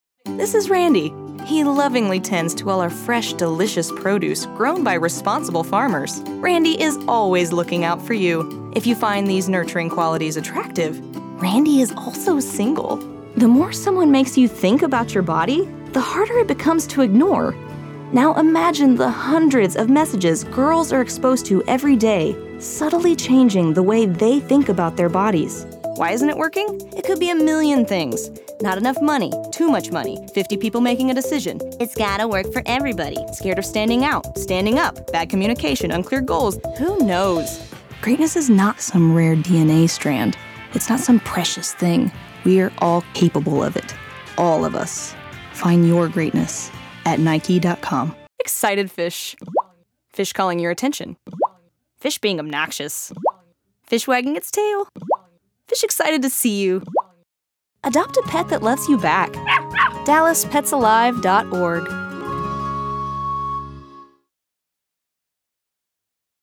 Professional Female Voice Over Talent
Good voice, not the same old, same old, with just the right amount of character.